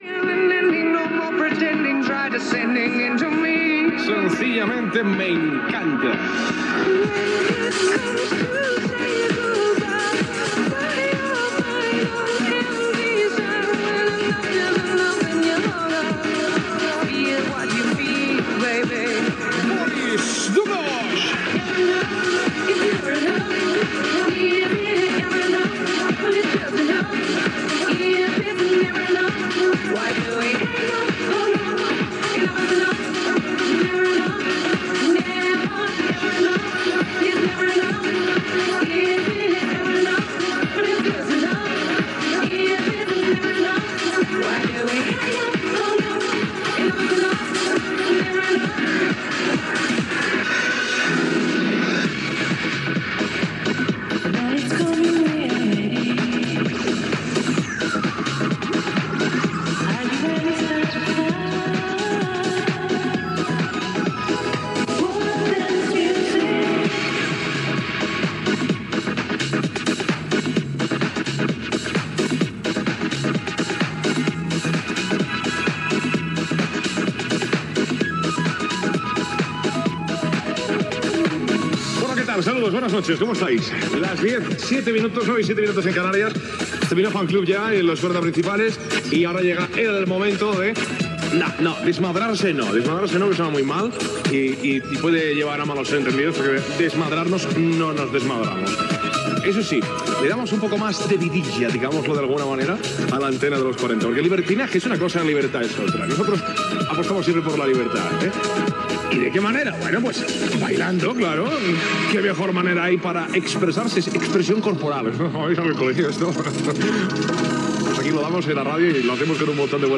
Tema musical, salutació inicial, hora, presentació del programa, indicatiu, esment a un oïdor de València i tema musical
Musical